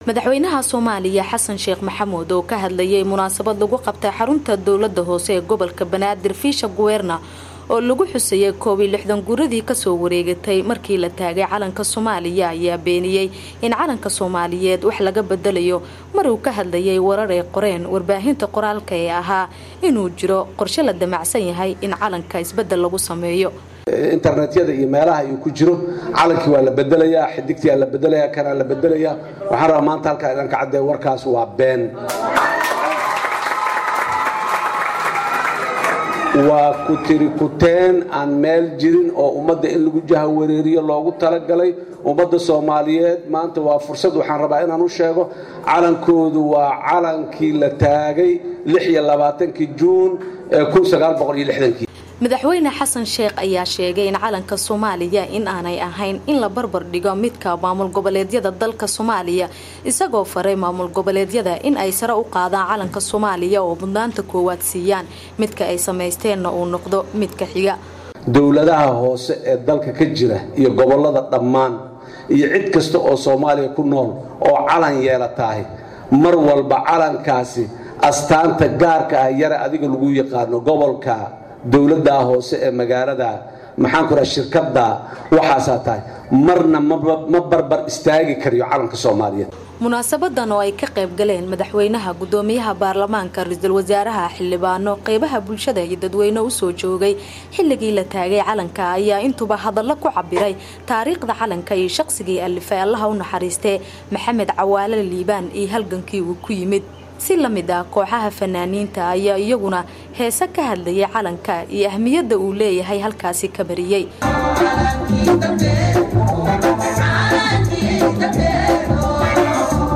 Madaxweynaha oo hadal ka jeediyey munaasabadda, ayaa waxa uu ka jawaabay warar baraha internet-ka lagu qoray oo ah in wax laga beddelayo calanka Soomaaliya.